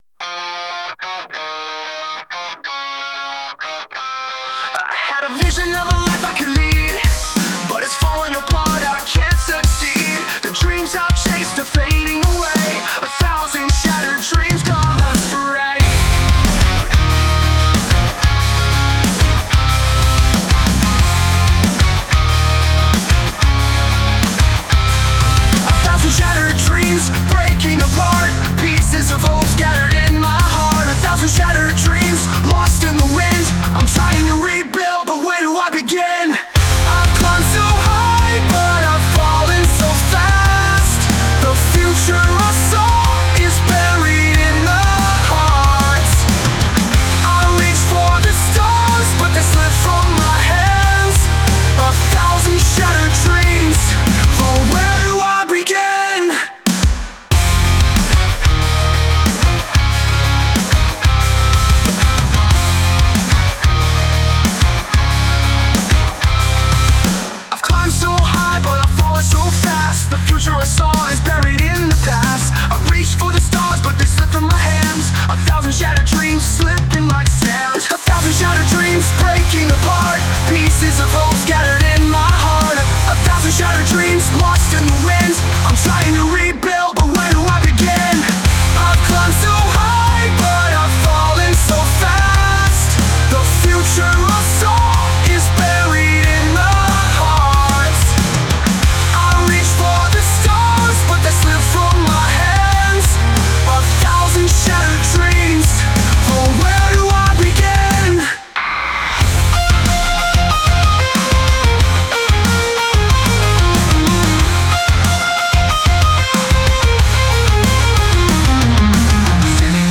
Genre: METAL